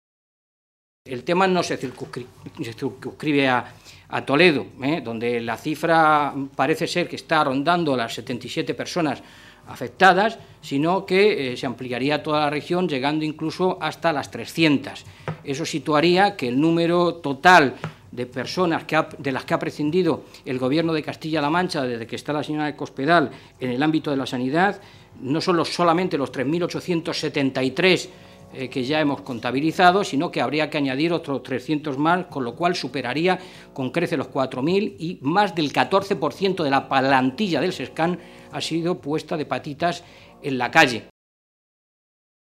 Matilde Valentín junto a Fernando Mora durante la rueda de prensa